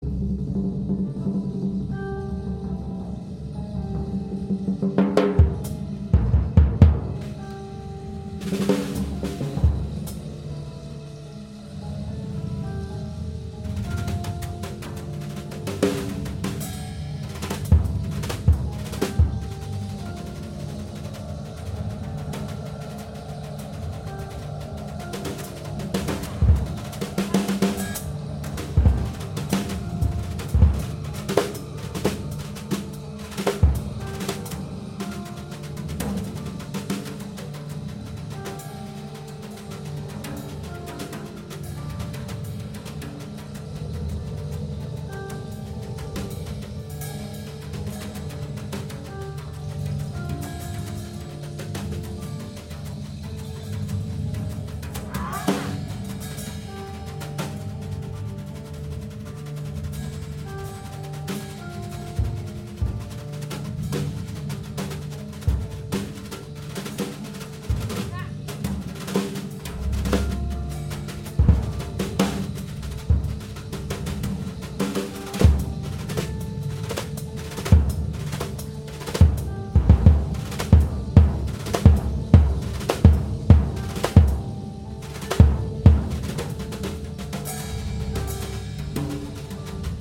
2021年に Cafe OTO で録音され、昨年カセットテープでリリースされていたソロでの音源がレコードでも登場。
ドラム、オブジェクト、声、エレクトロアコースティックで構成された46分怒涛のロングフォーム。